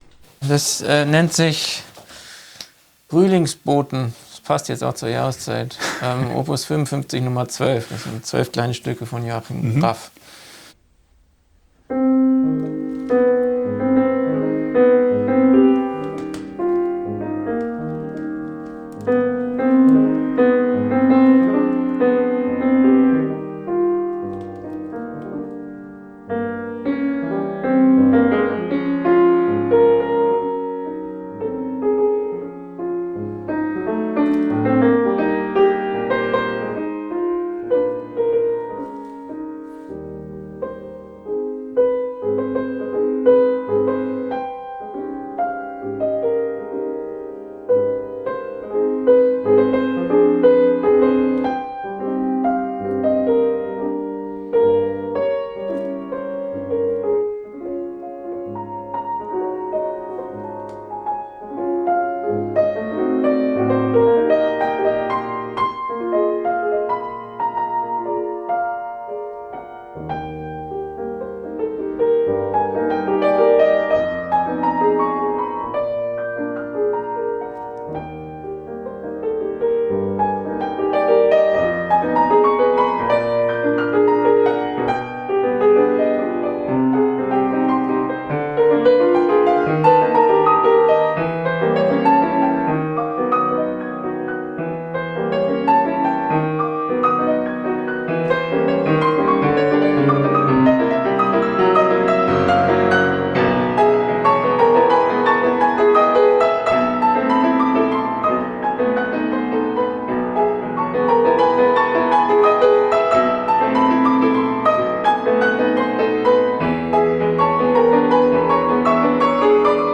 Große Star-Solisten und junge Kammermusiker, erfahrene Orchestermitglieder und hoffnungsvolle Talente sprechen über das Musikerleben und die Musik, über ungewöhnliche Instrumente, vergessene Komponisten und hilfreiche Ohrwürmer. Dazu gibt es Live-Musik und Tipps gegen die Corona-Tristesse.